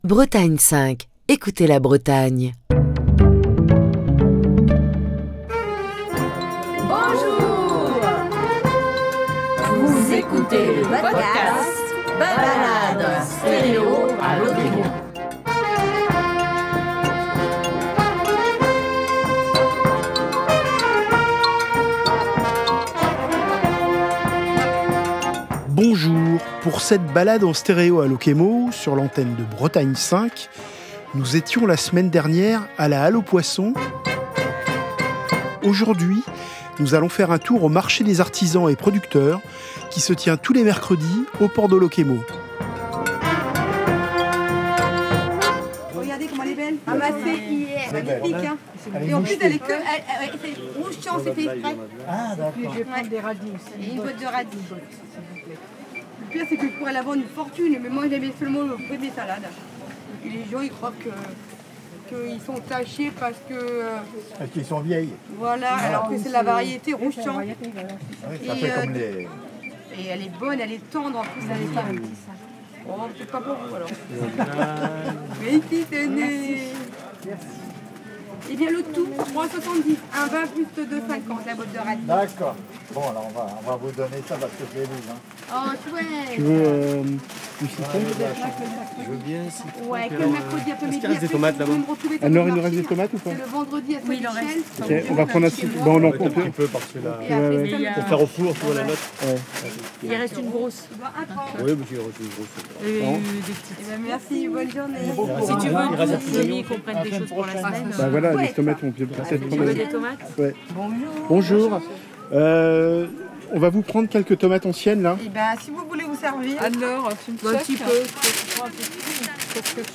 Aujourd’hui, nous allons faire un tour au marché des artisans et producteurs, qui se tient tous les mercredis au port de Locquémeau, et nous faisons un détour par l’école de voile où les enfants apprennent à se confronter à la mer sur leurs Optimist.